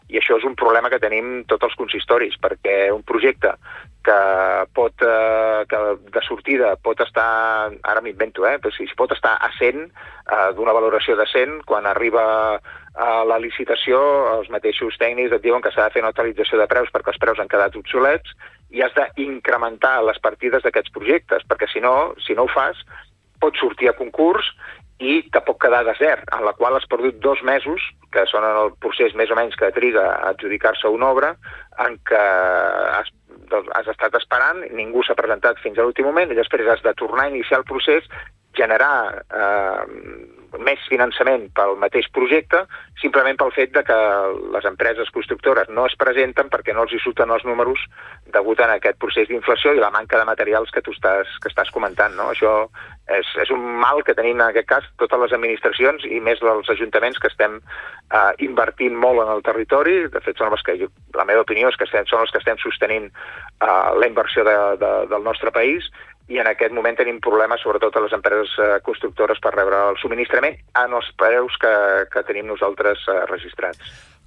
Entrevistes SupermatíNotícies
Per això, ens ha visitat al Supermatí l’alcalde del muncipi, Carles Motas.